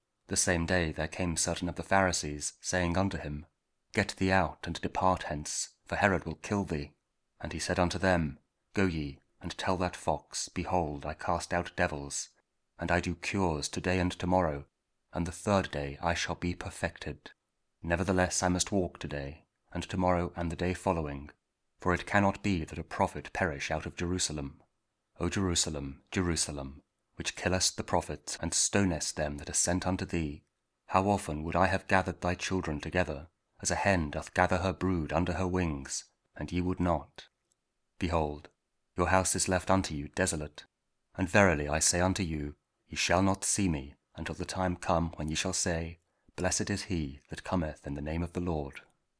Luke 13: 31-35 – Week 30 Ordinary Time, Thursday (King James Audio Bible KJV, Spoken Word)